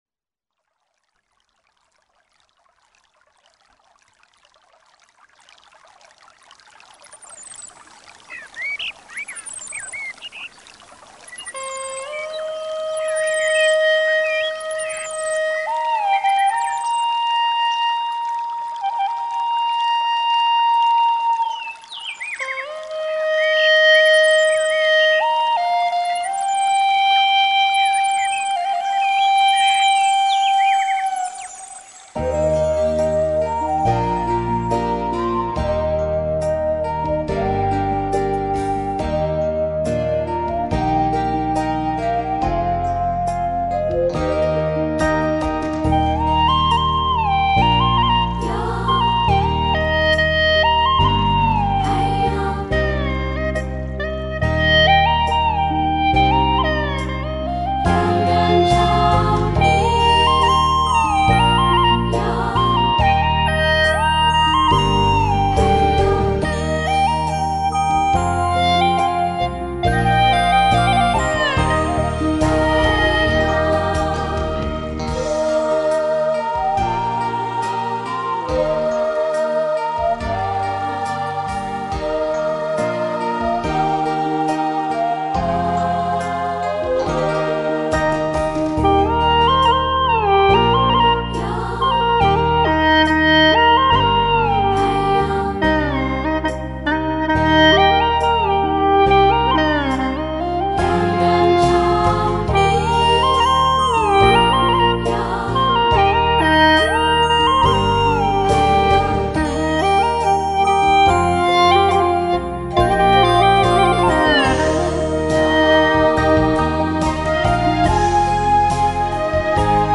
调式 : D 曲类 : 民族